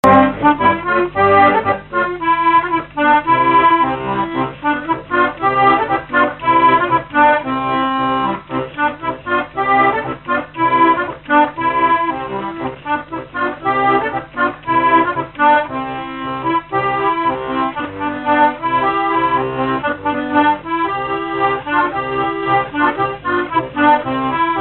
Mémoires et Patrimoines vivants - RaddO est une base de données d'archives iconographiques et sonores.
Chants brefs - A danser
danse : polka des bébés ou badoise
Répertoire à l'accordéon diatonique
Pièce musicale inédite